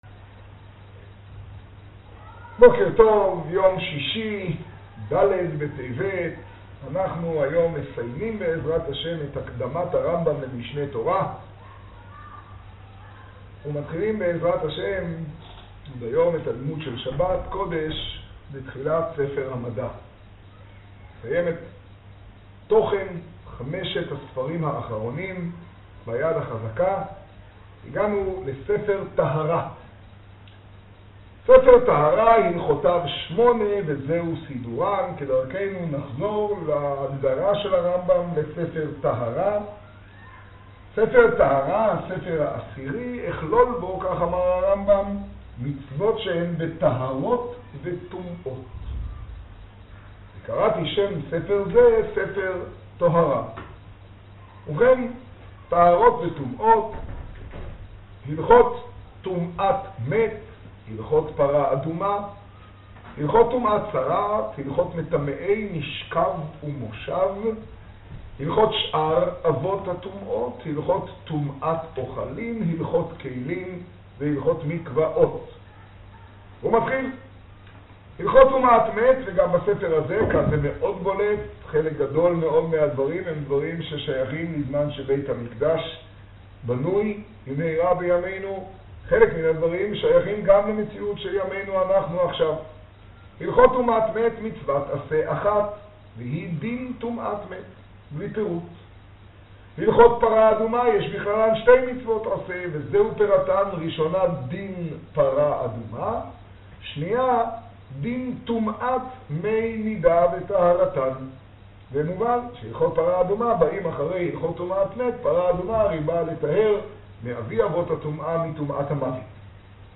השיעור במגדל, ד טבת תשעה.